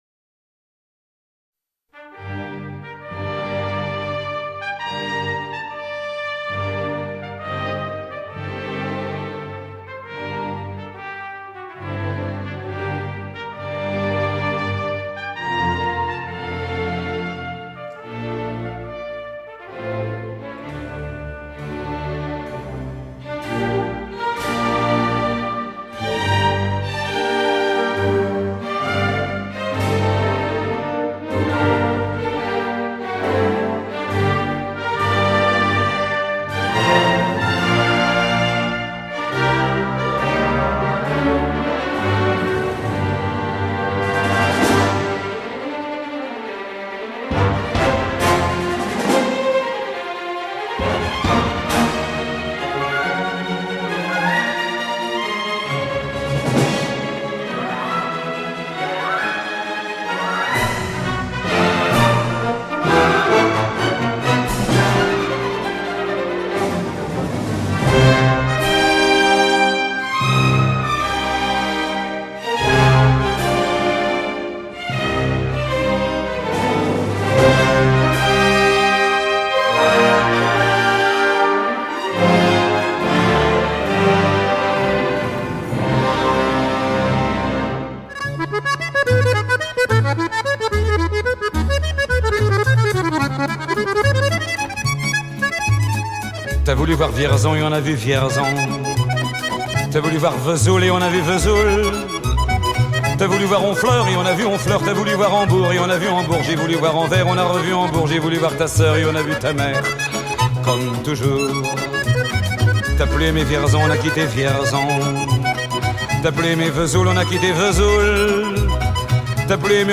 Plutôt classique, funk, ou jazz ?